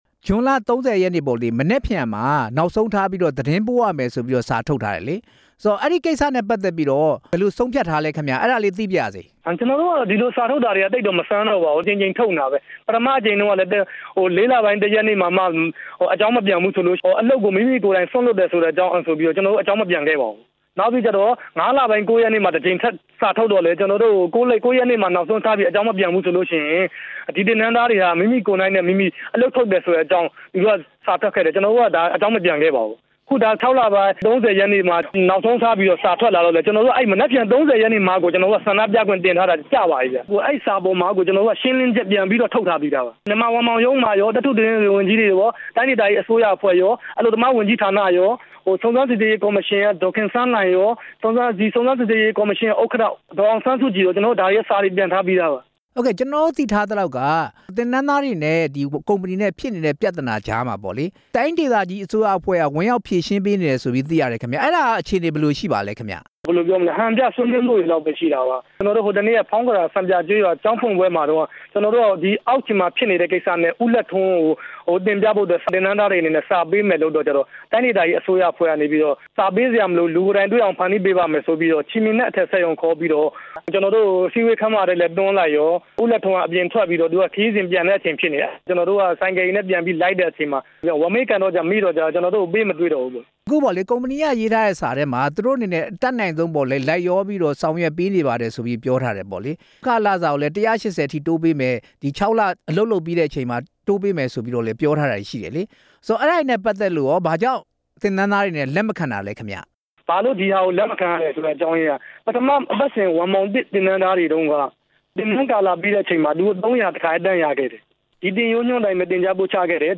ဝမ်ပေါင်ကုမ္ပဏီ ဆန္ဒပြ ဝန်ထမ်းနဲ့မေးမြန်းချက်